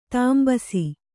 ♪ tāmbasi